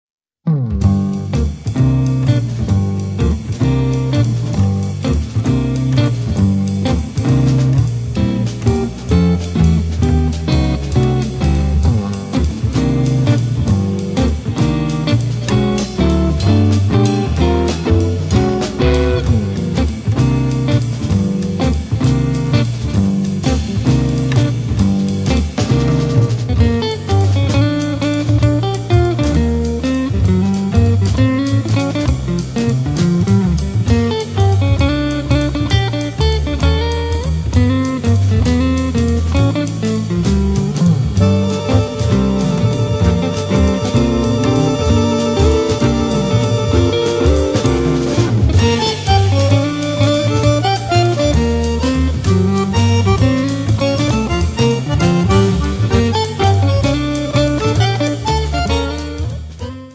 chitarra elettrica e acustica, effetti
fisarmonica, elettroniche
contrabbasso
batteria
con la chitarra baritono